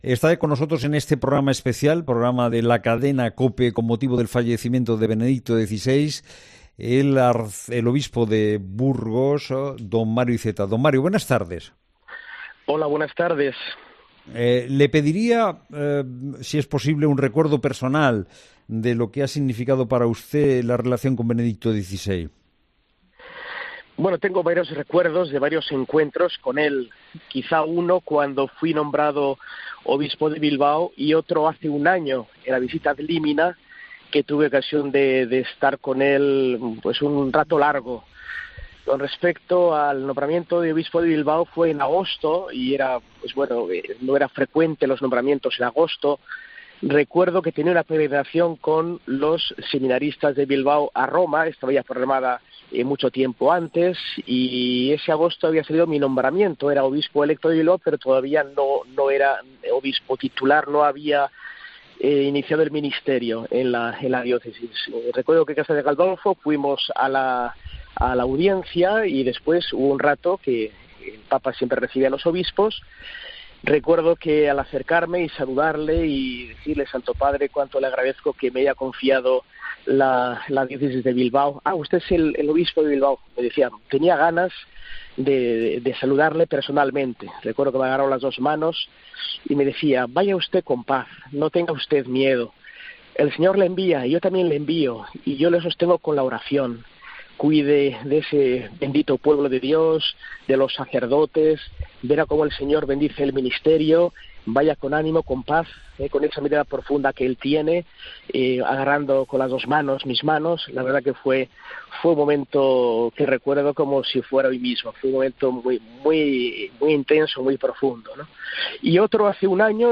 El arzobispo de Burgos, Mario Iceta, ha pasado por los micrófonos de COPE para recordar dos momentos que vivió junto a Benedicto XVI en 2010, tras ser nombrado obispo de Bilbao, y en 2021 durante la visita 'ad limina'.